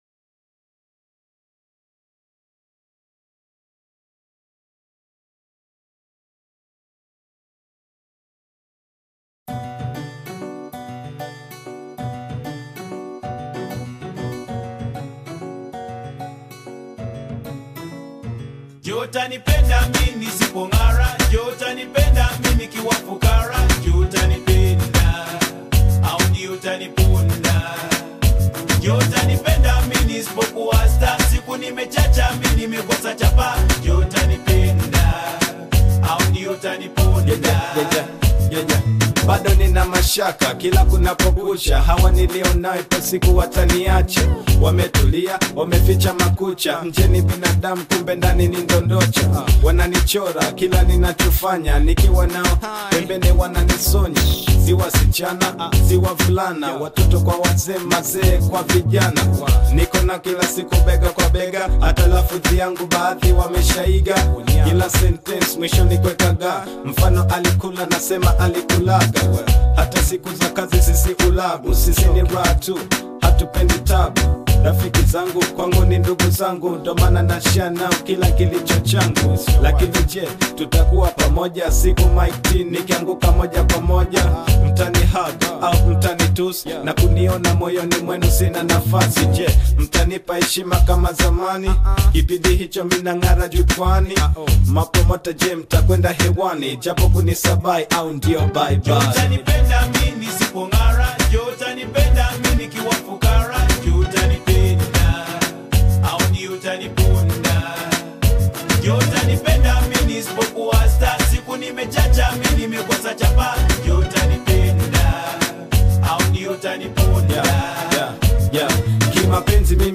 classic Bongo Fleva
Swahili love ballad